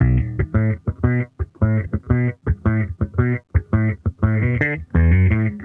Index of /musicradar/sampled-funk-soul-samples/85bpm/Bass
SSF_JBassProc2_85B.wav